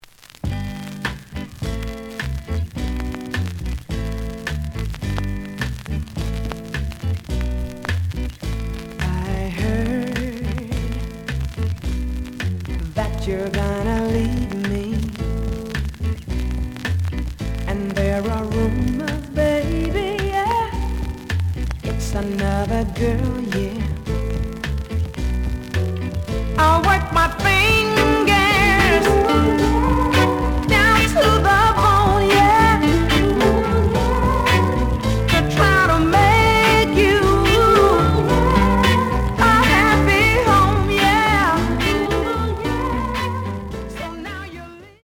The audio sample is recorded from the actual item.
●Genre: Soul, 60's Soul
Some noise on both sides.)